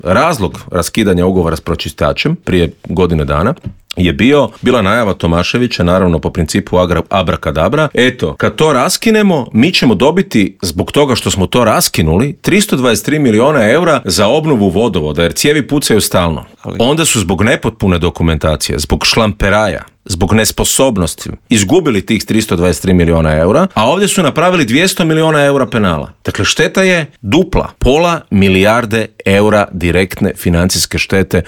U studiju Media servisa ugostili smo nezavisnog kandidata za gradonačelnika Zagreba Davora Bernardića kojeg uvjerljivi trijumf Milanovića nije iznenadila jer su trendovi bili neupitni: